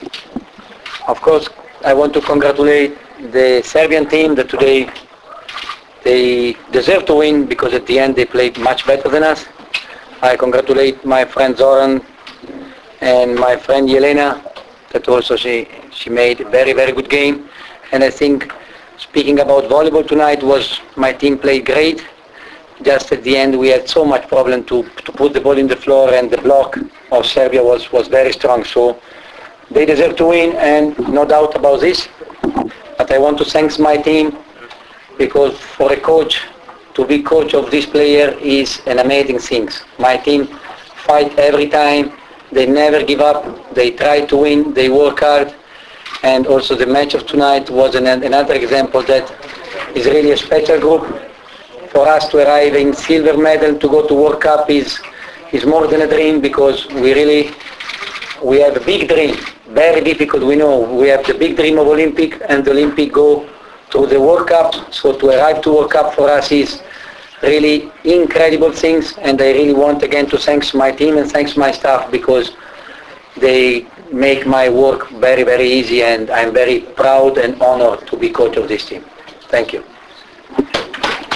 IZJAVA ĐOVANIJA GVIDETIJA